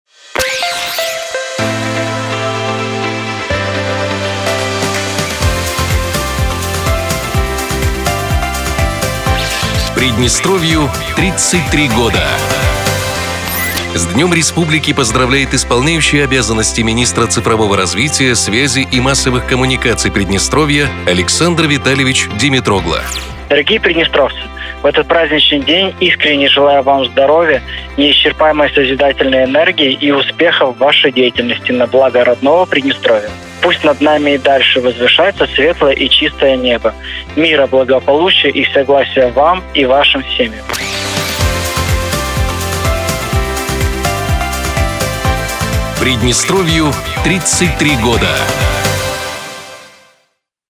Слова поздравлений также прозвучали в эфире Радио 1 .